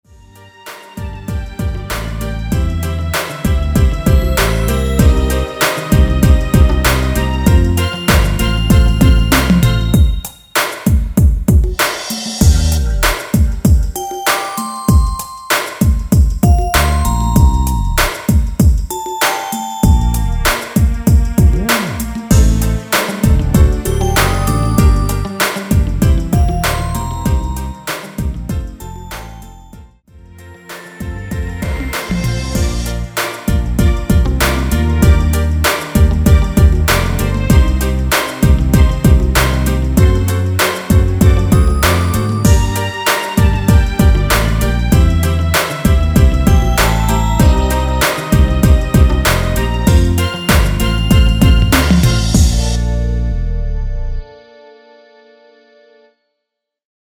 중간 간주 랩 없이 진행되고 마지막 랩 없이 끝납니다.(본문 가사 확인)
원키에서(-2)내린 랩부분 삭제 편곡한 MR 입니다.(미리듣기 참조)
Bb
앞부분30초, 뒷부분30초씩 편집해서 올려 드리고 있습니다.